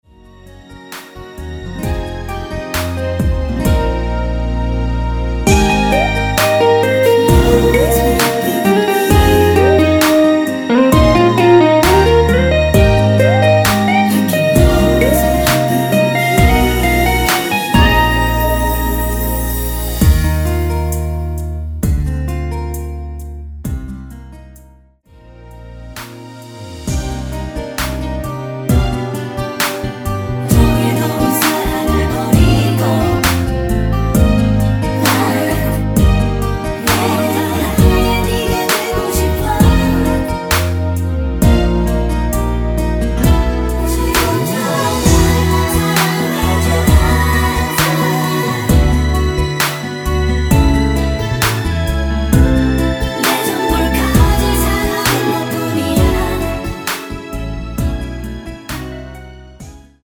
원키에서(+3)올린 코러스 포함된 MR입니다.(미리듣기 확인)
Eb
앞부분30초, 뒷부분30초씩 편집해서 올려 드리고 있습니다.